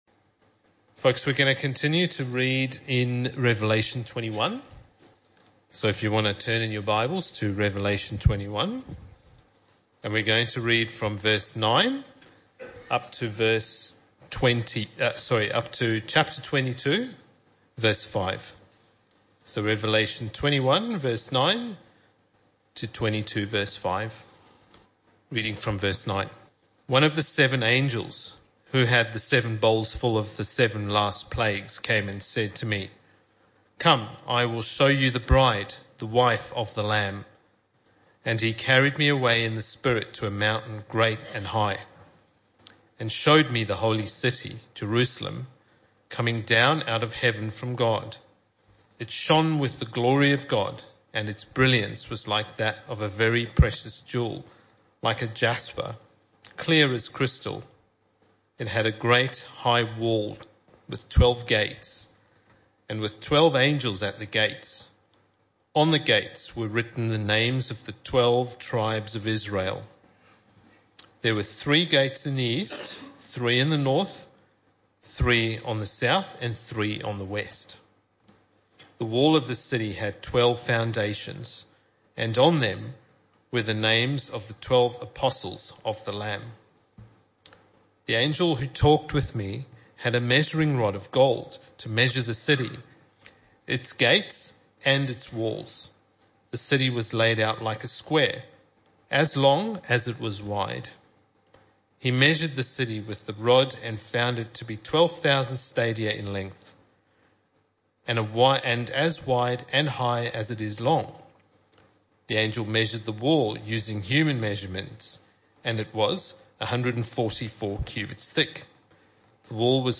Weekly sermons, other bible talks and occasional special events from WPC Bull Creek